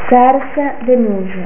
(pronuncia)   noci, olio, burro